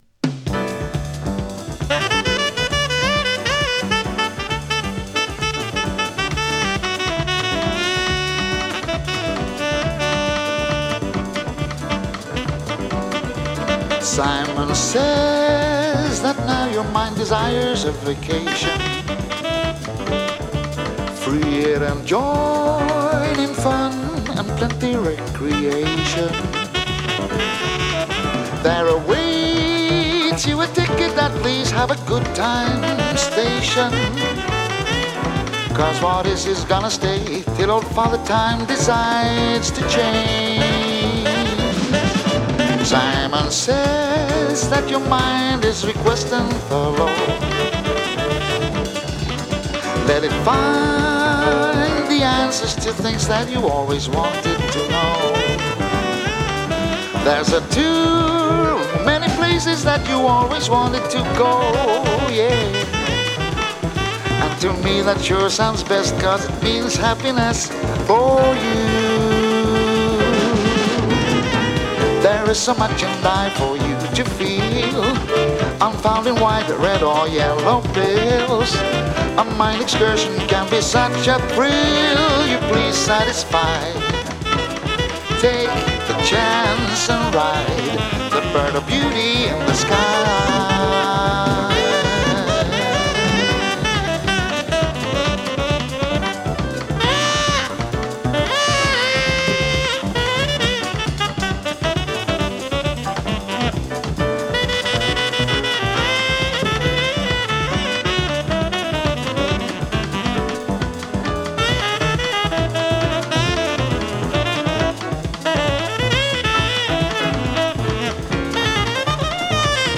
軽快
スウェーデンのボーカリストによる最高傑作。
ジャジーなアレンジが素晴らしい大人気盤です！